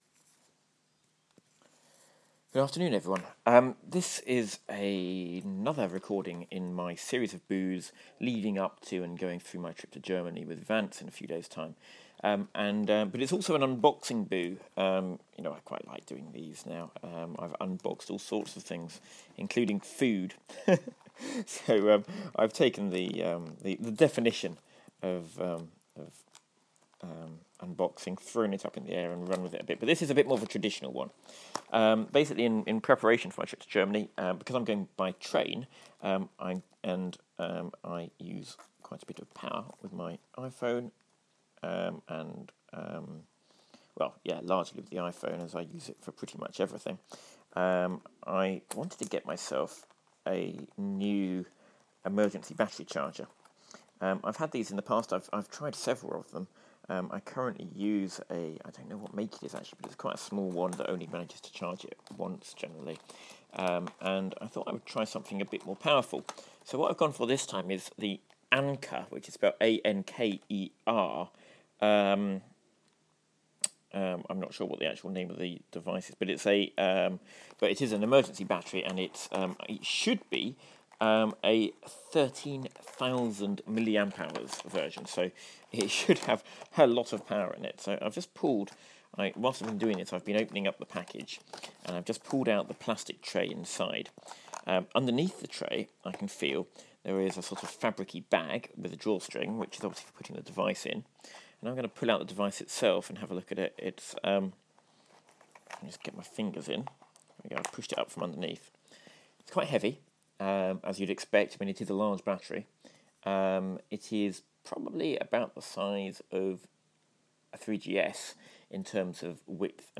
This recording sees me unboxing my newest power gadget ahead of my departure to the continent.